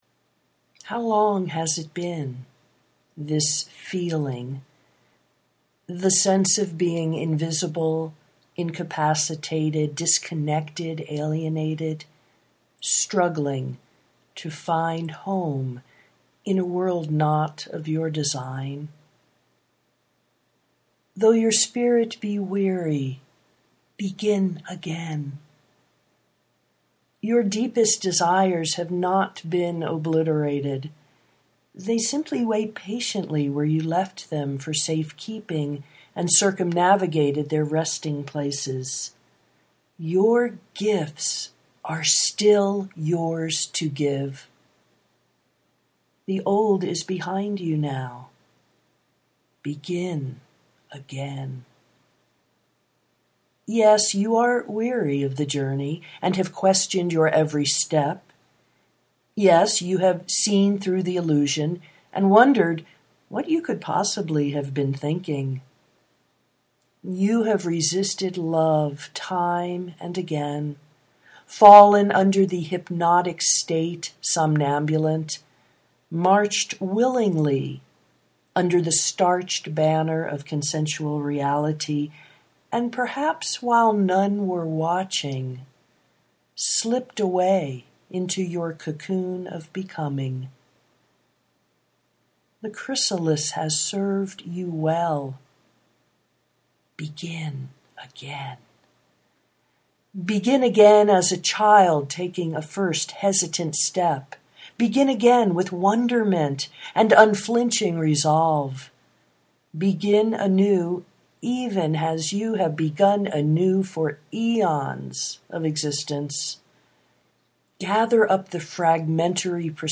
begin again (classic w/new audio poetry 5:03)